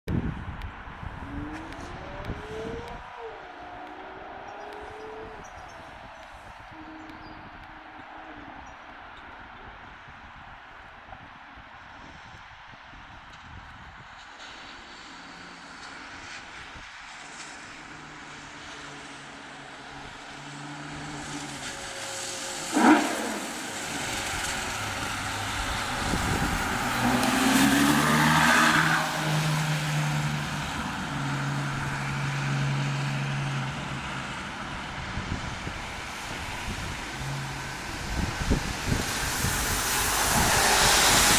🩶Ferrari FF V12 🔊SOUND ON🔊🩶 Sound Effects Free Download